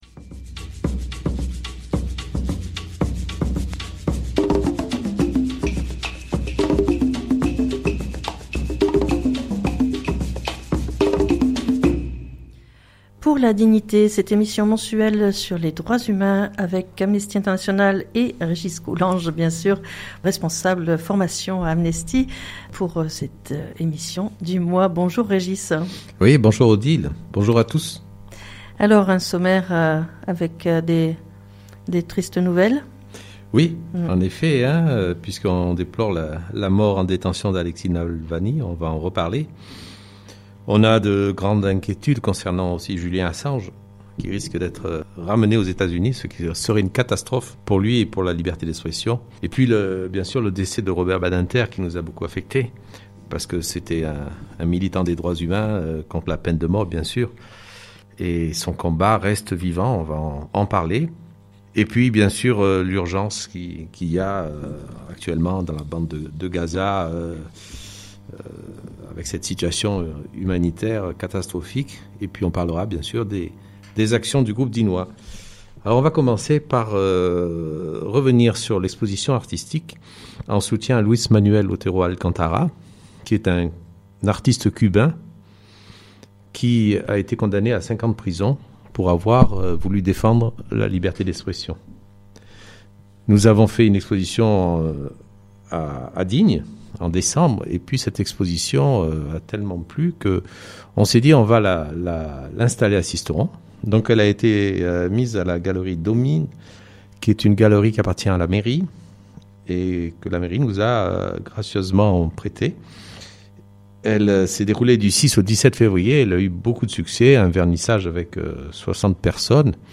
Robert Badinter est mort mais son combat reste bien vivant - Interview à Amnesty